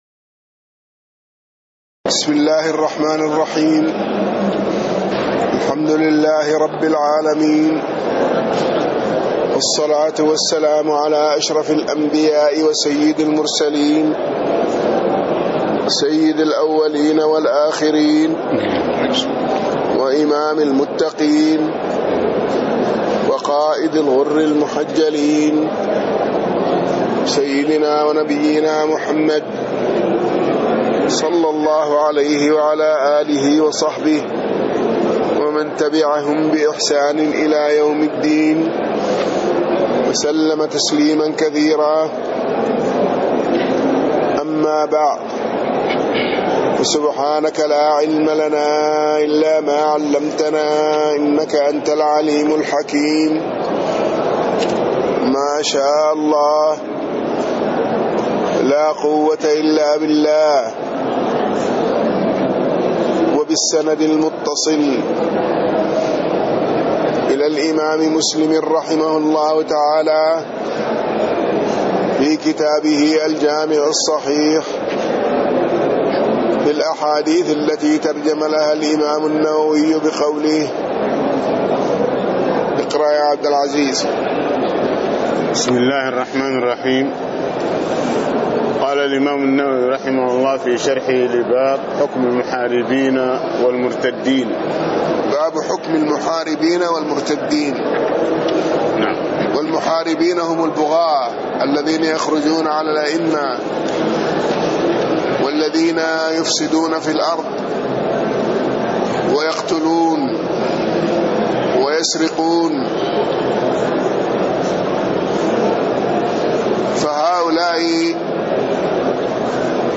تاريخ النشر ٢ جمادى الآخرة ١٤٣٥ هـ المكان: المسجد النبوي الشيخ